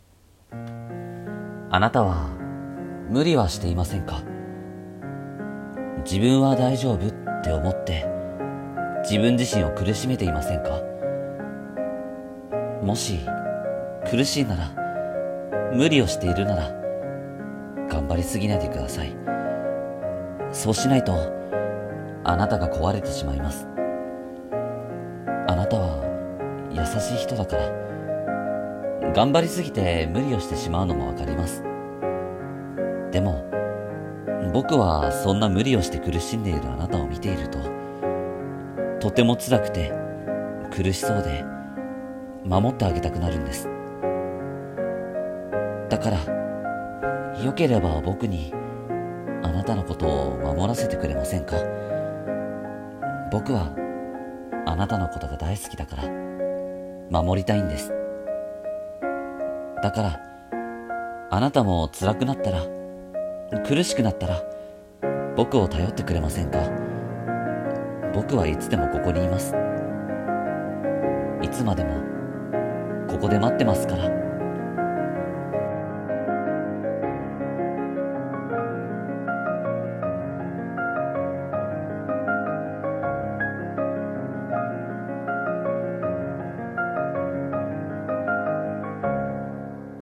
【一人声劇】頑張りすぎているあなたへ